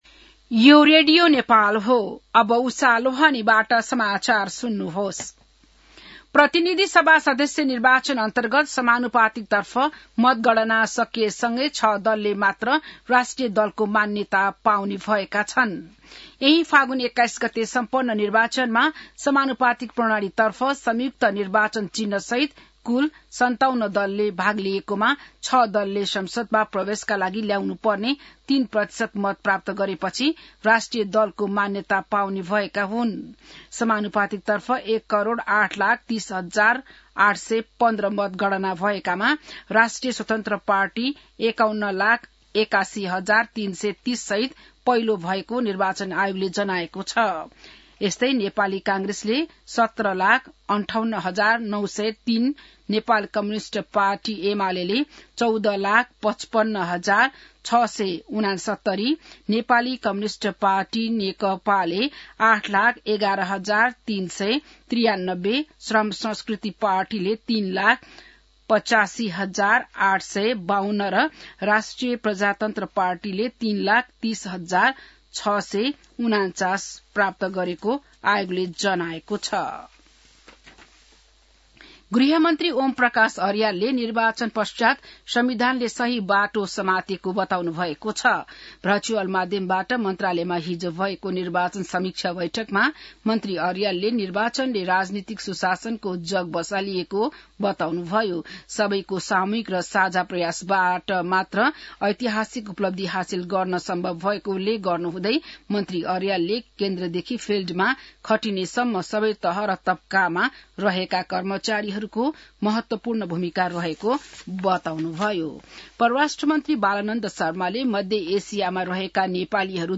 बिहान १० बजेको नेपाली समाचार : २८ फागुन , २०८२